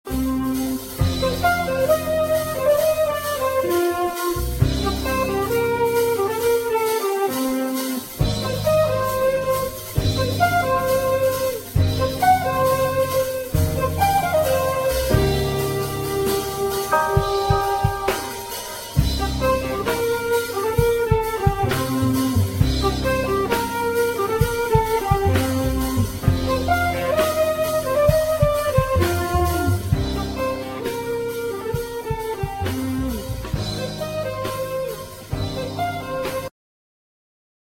ジャンル Jazz
Progressive
癒し系
東洋とも西洋とも言えない新しい宇宙サウンドを聴かせてくれる